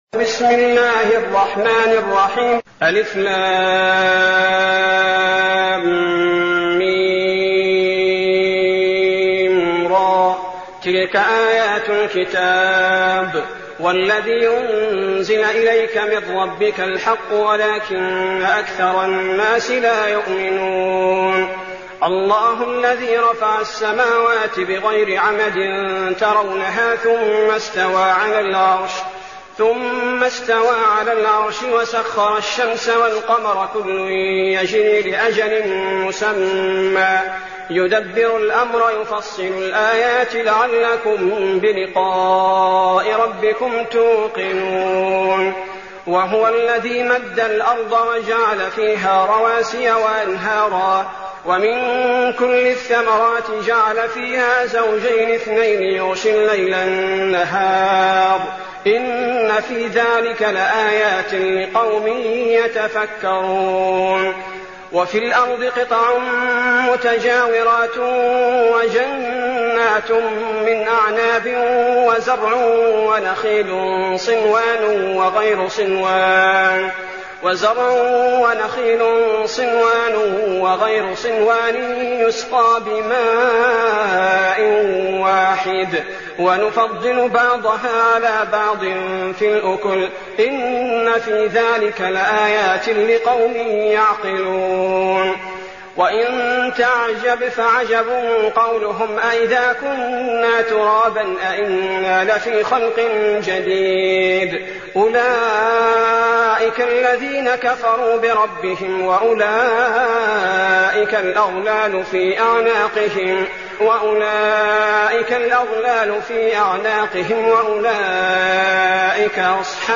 المكان: المسجد النبوي الشيخ: فضيلة الشيخ عبدالباري الثبيتي فضيلة الشيخ عبدالباري الثبيتي الرعد